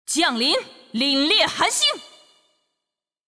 技能语音